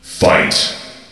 fight.ogg